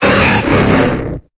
Cri d'Élecsprint dans Pokémon Épée et Bouclier.